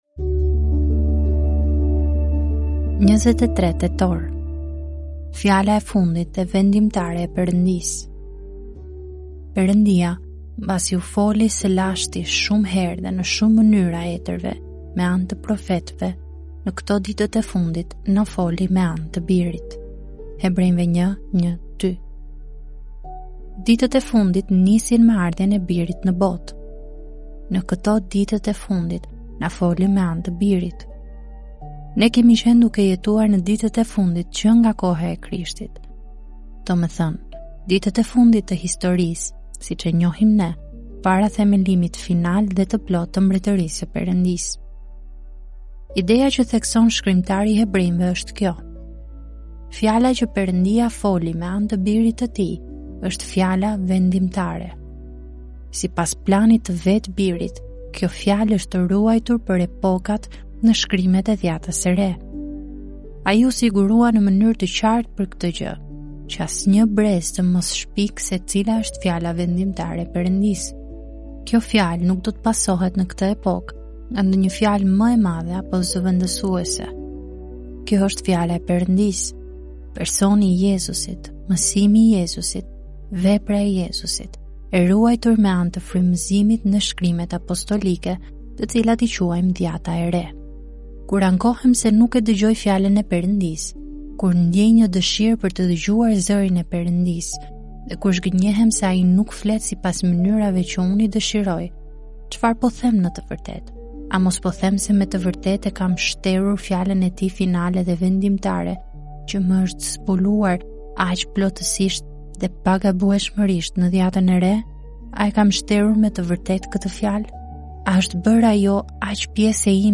"Solid Joys" janë lexime devocionale të shkruara nga autori John Piper.